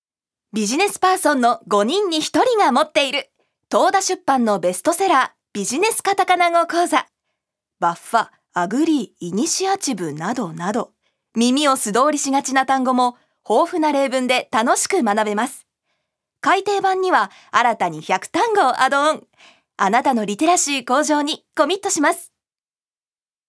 預かり：女性
ナレーション１